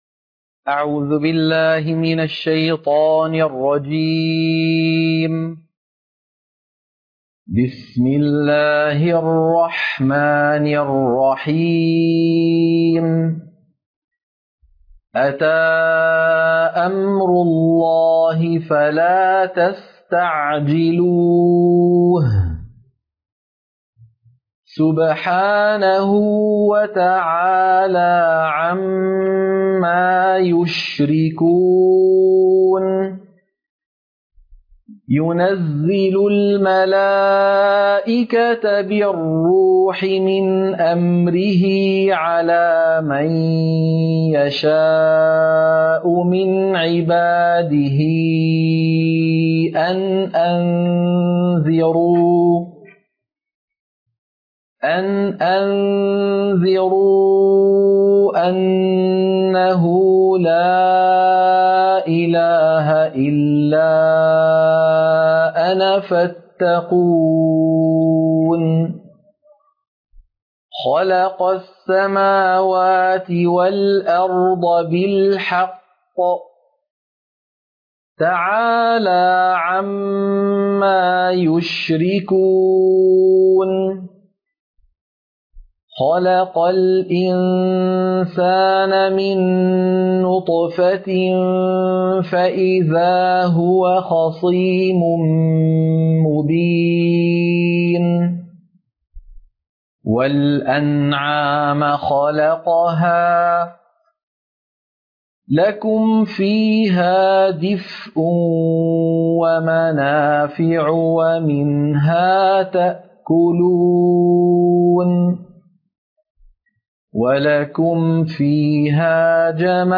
سورة النحل - القراءة المنهجية